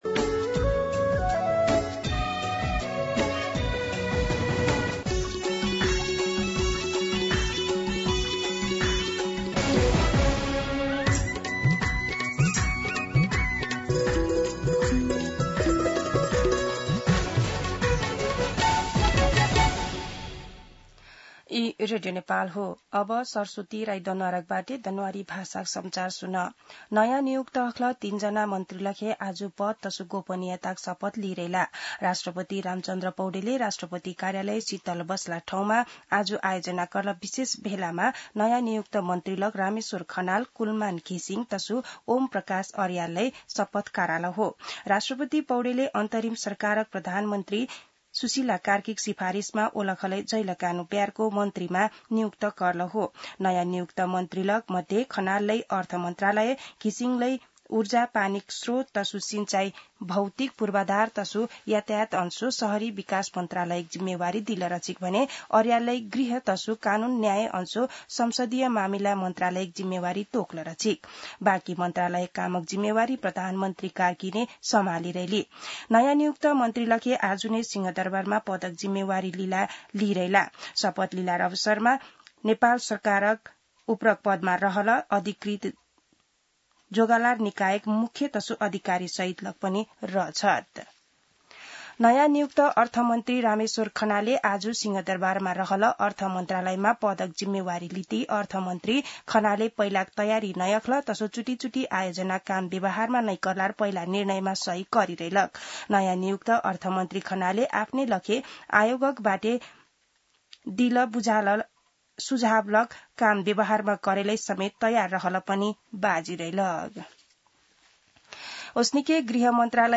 दनुवार भाषामा समाचार : ३० भदौ , २०८२